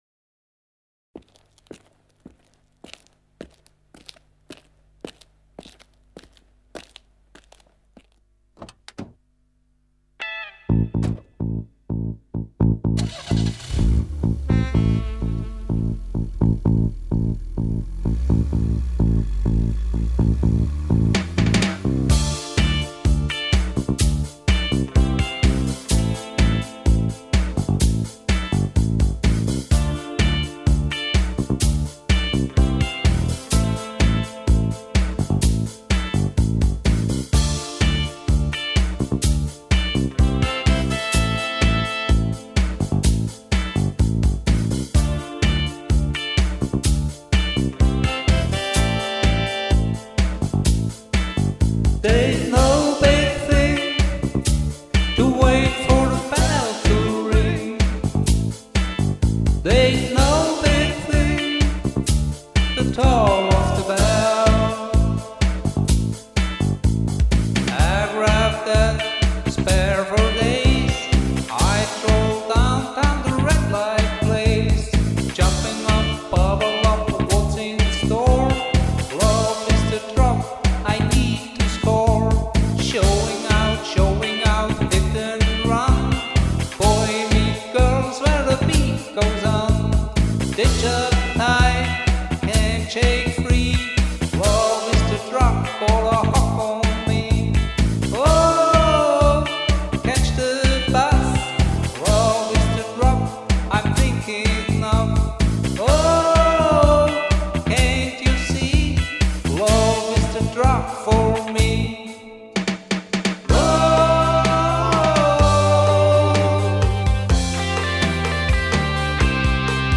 Karaoke MP3 Version